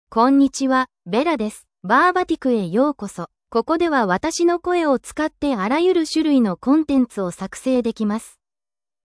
BellaFemale Japanese AI voice
Bella is a female AI voice for Japanese (Japan).
Voice sample
Listen to Bella's female Japanese voice.
Female
Bella delivers clear pronunciation with authentic Japan Japanese intonation, making your content sound professionally produced.